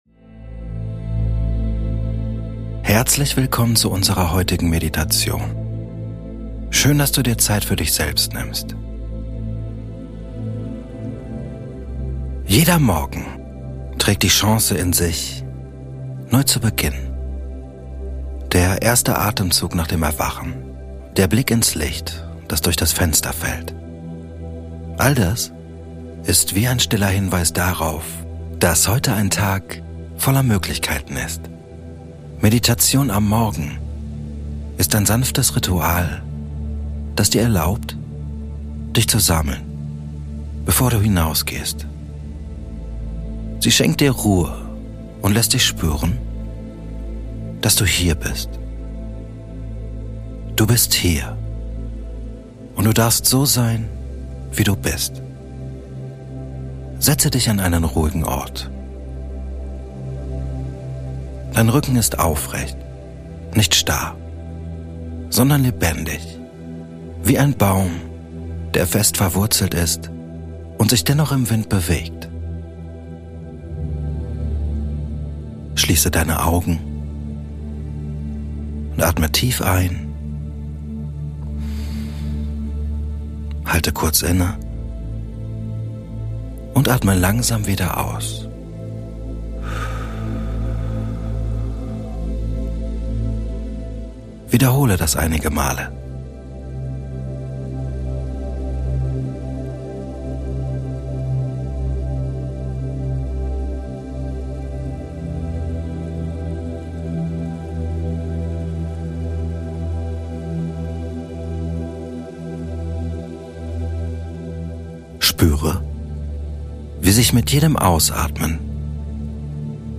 Morgen Meditation - Entfalte dein Selbstbewusstsein ~ Kopfkanal - Geführte Meditationen Podcast